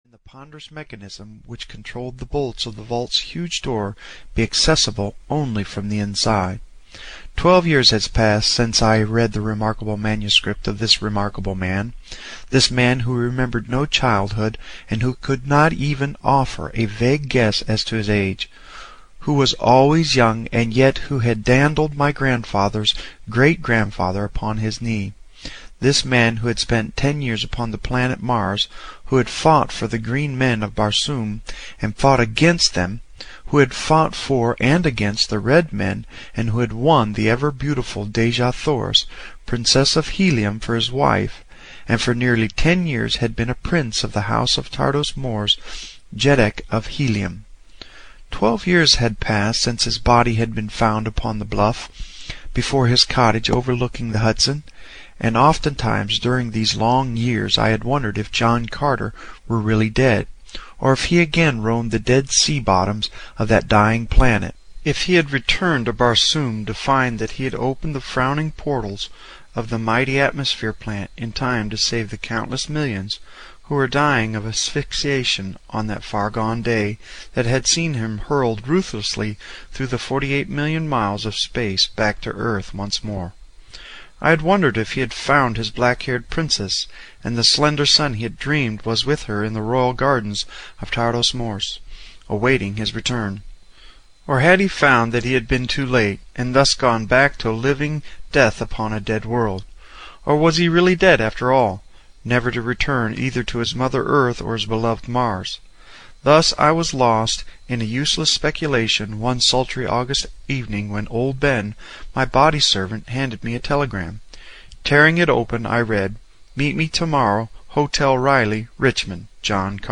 The Gods of Mars (EN) audiokniha
Ukázka z knihy